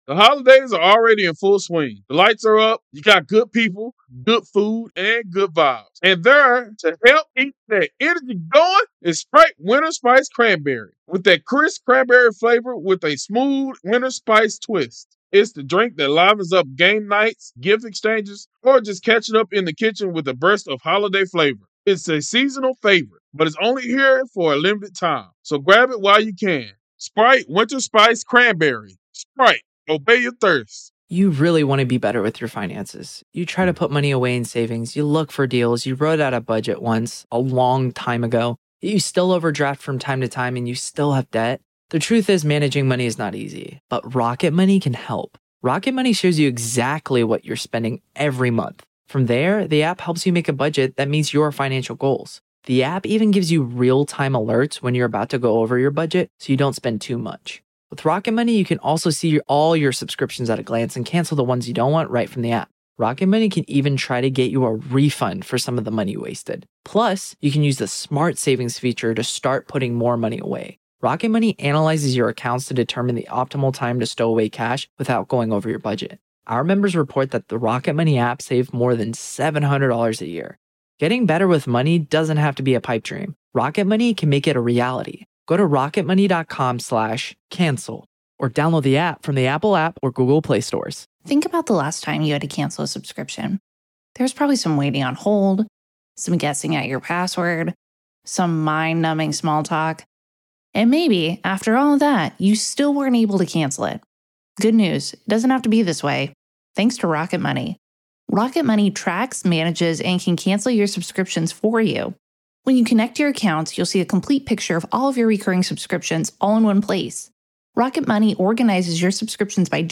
The Trial Of Alex Murdaugh | FULL TRIAL COVERAGE Day 19 - Part 3